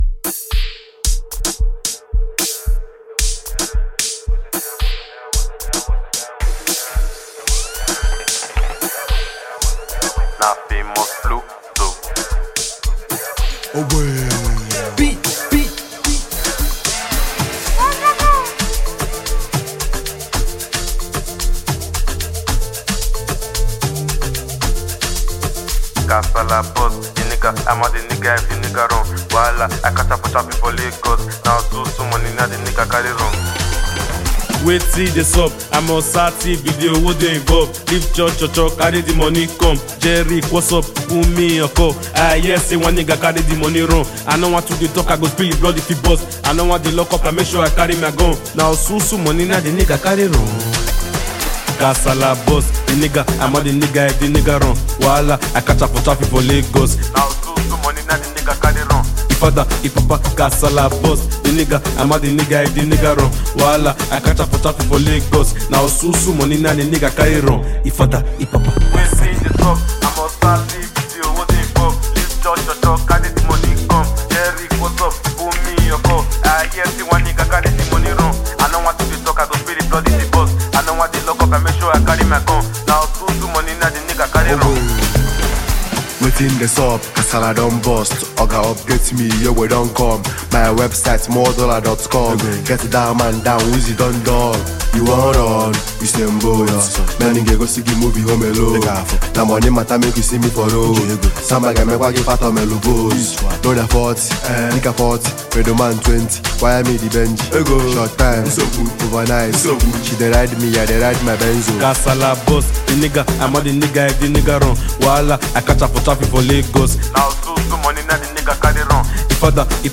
With its infectious rhythm and compelling vibe